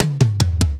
DIRTY_drum_fill_tom_01_148.wav